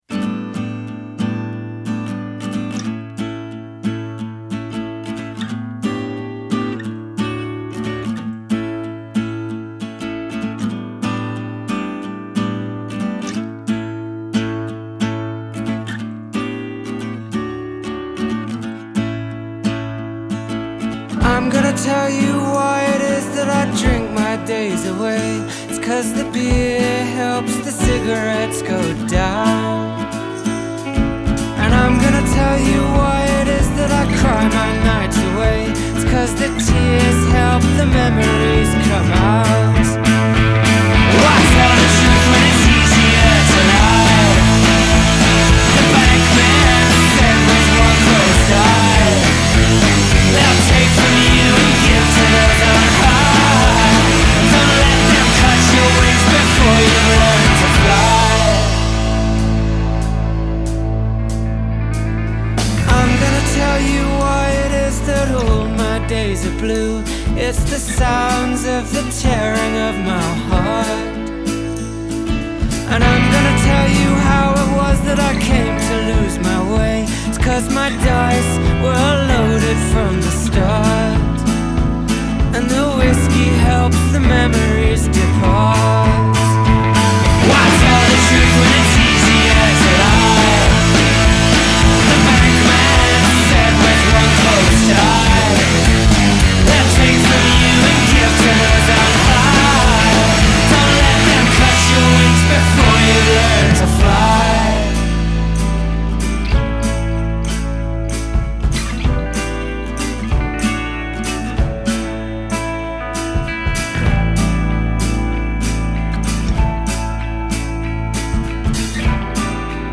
drums
guitar/vocals
bass